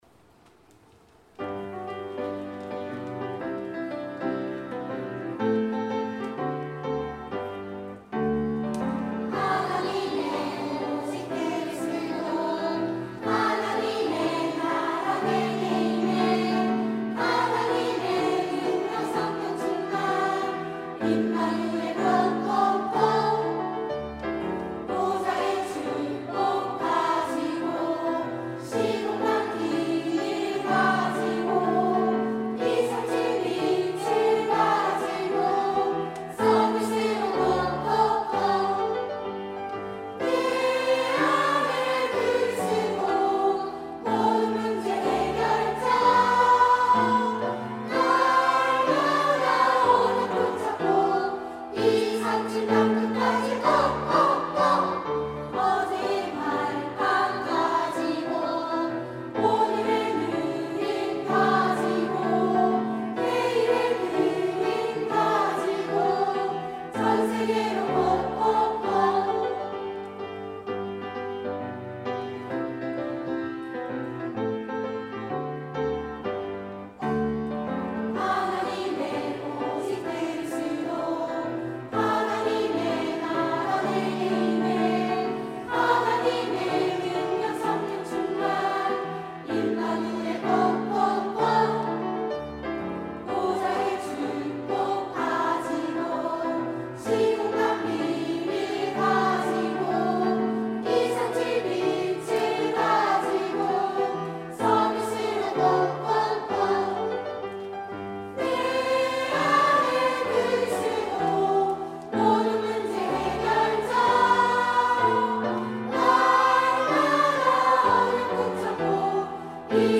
3부 찬양대(~2024)